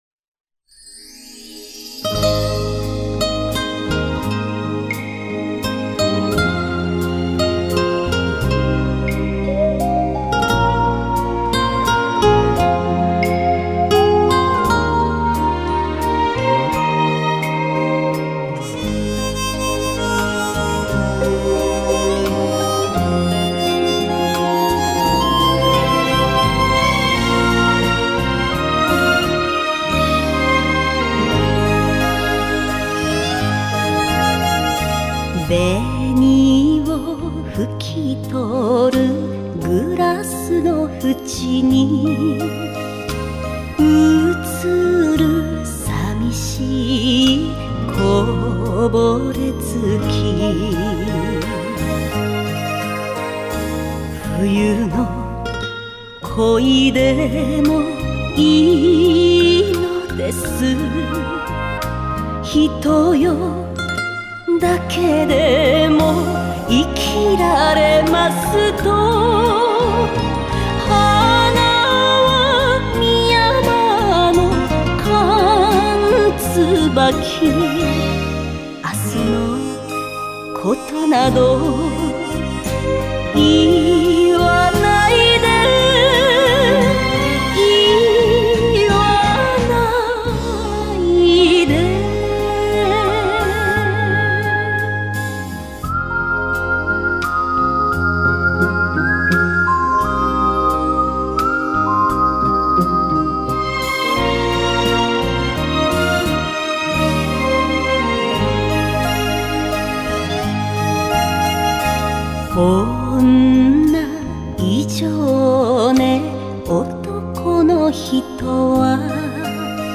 好听的演歌